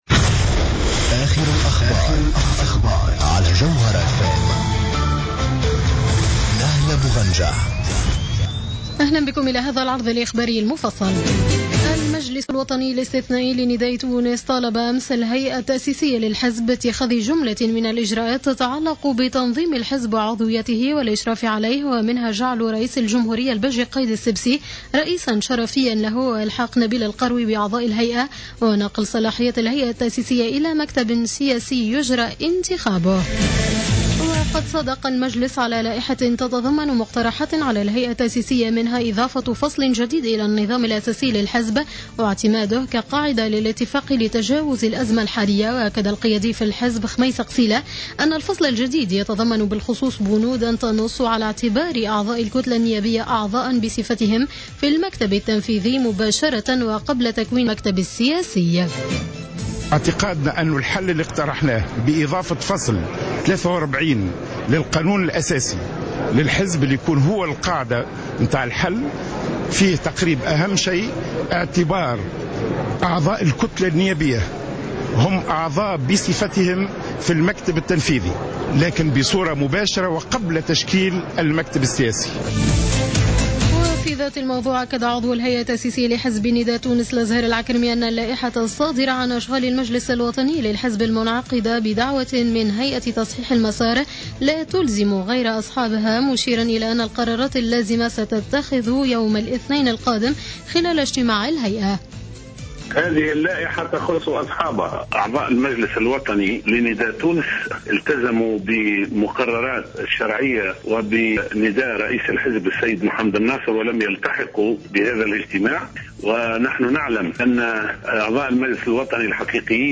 نشرة أخبار منتصف الليل ليوم الأحد 15 مارس 2015